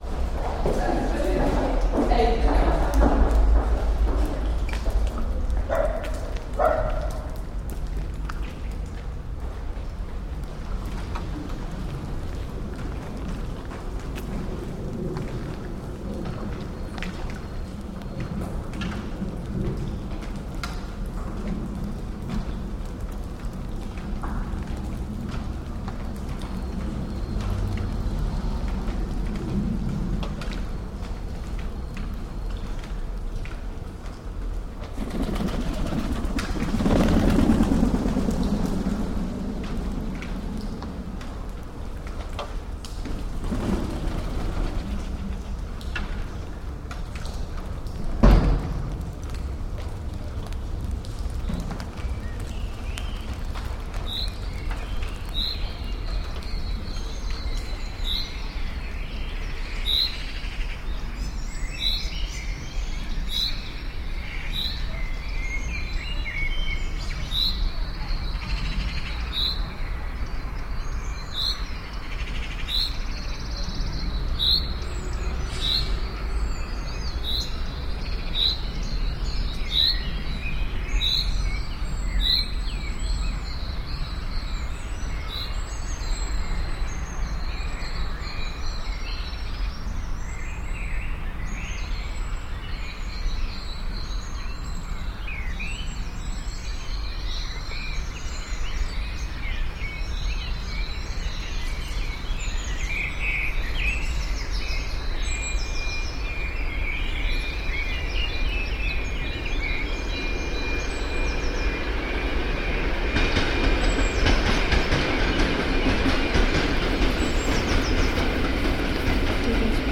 These are field recordings from a trip to Krakow / Auschwitz-Birkenau.
phonography / field recording; contextual and decontextualized sound activity